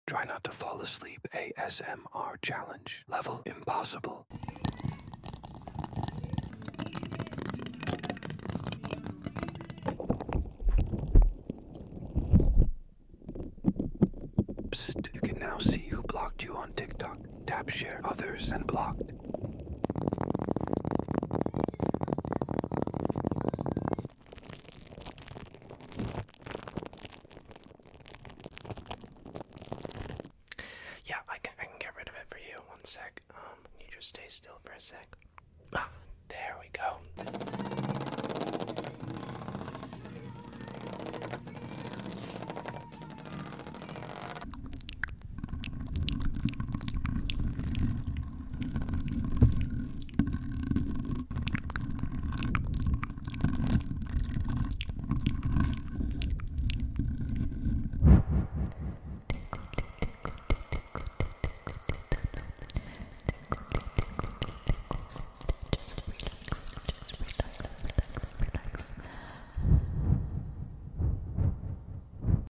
Upload By ASMR